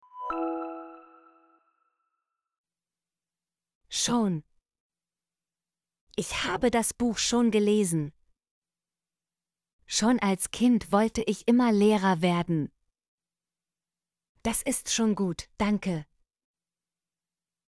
schon - Example Sentences & Pronunciation, German Frequency List